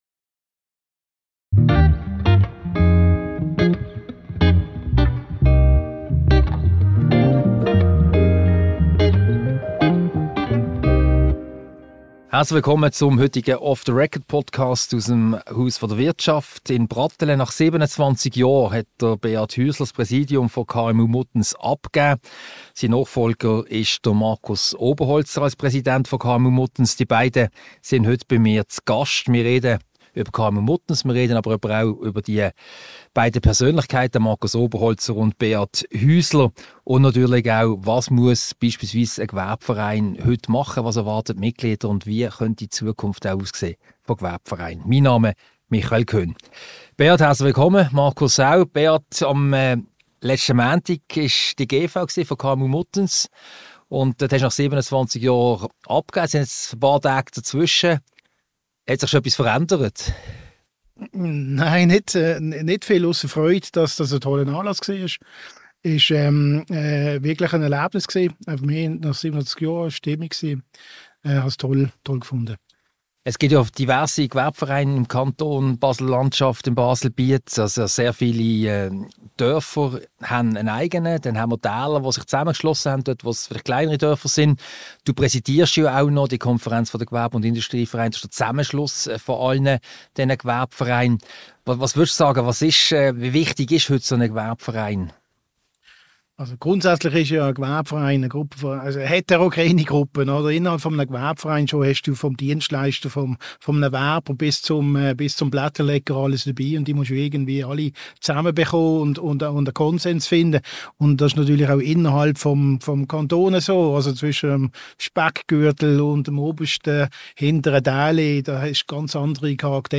Ein Gespräch über die vielfältigen Aufgaben von Gewerbevereinen, über Neues bei KMU Muttenz und über ganz persönliche Erfahrungen in der KMU-Wirtschaft.